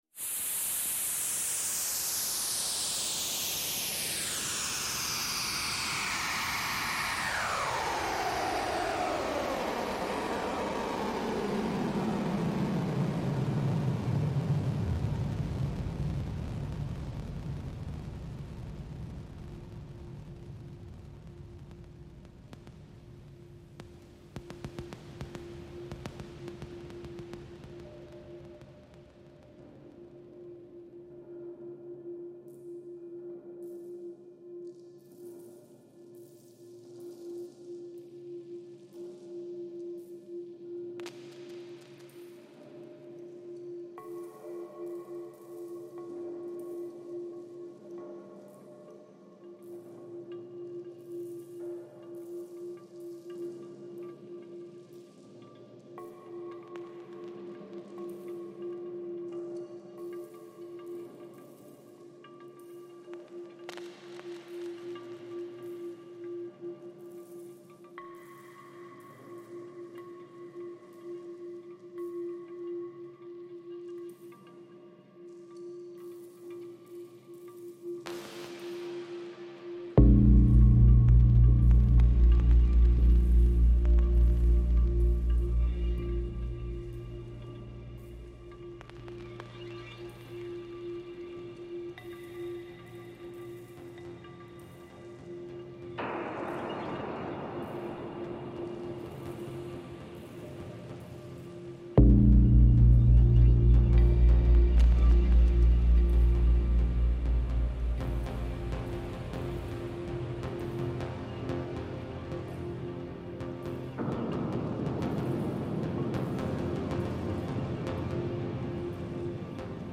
Incontro con il compositore, performer elettroacustico, ingegnere del suono e docente